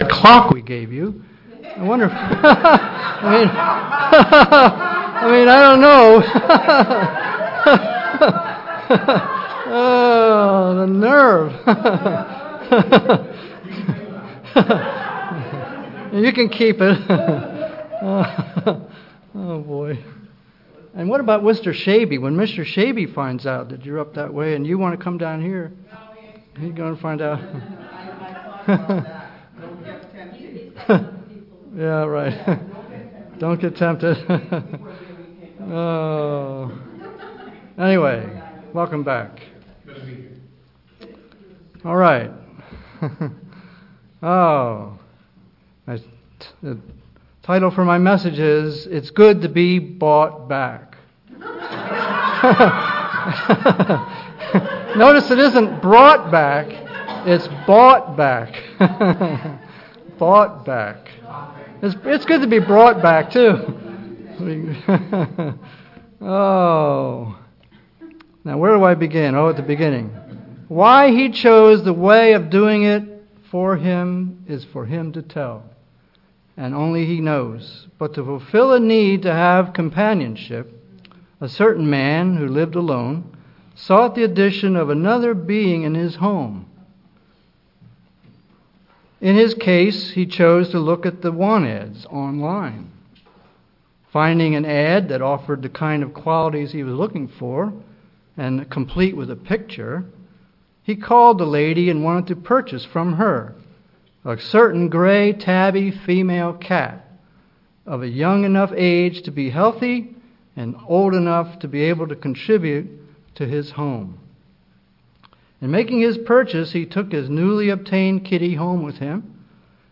Given in Vero Beach, FL
Some things to consider for Passover UCG Sermon Studying the bible?